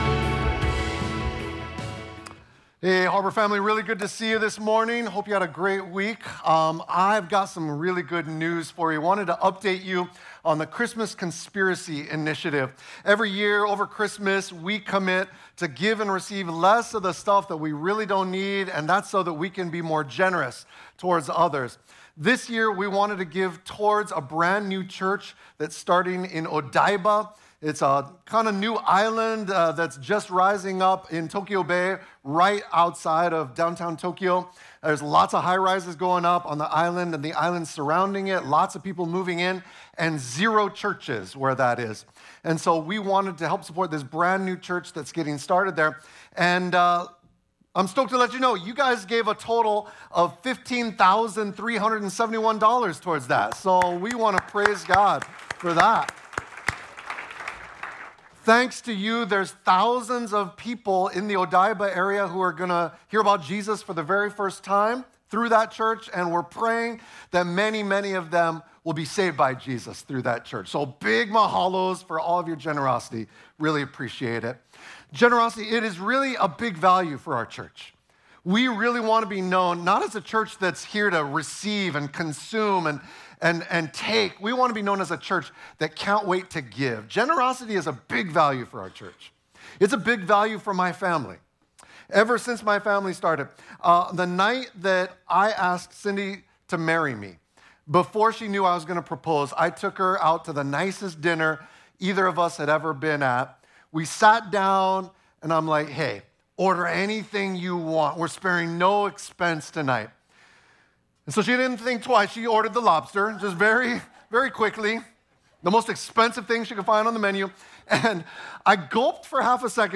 Sunday-Sermon-1.12.25.mp3